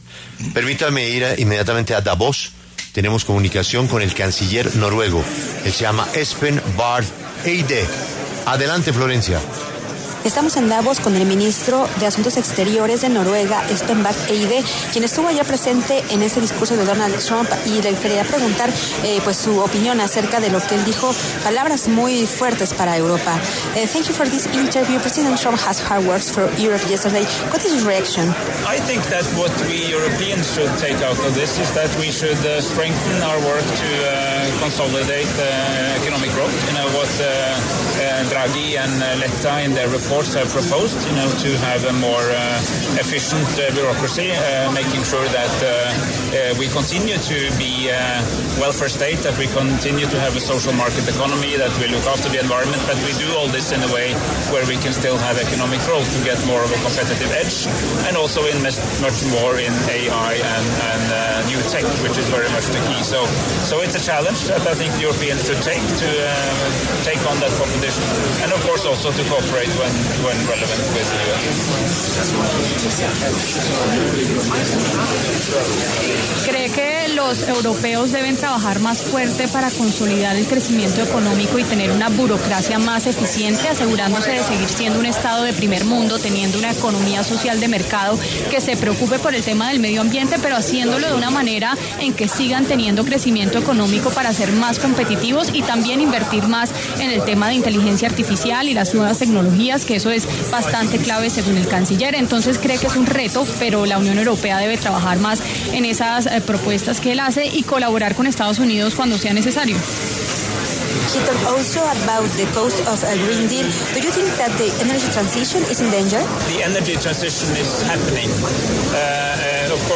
Espen Barth Eide, canciller de Noruega, pasó por los micrófonos de La W desde el Foro de Davos y se refirió al discurso de Donald Trump en el que lanzó “palabras muy fuertes para Europa”.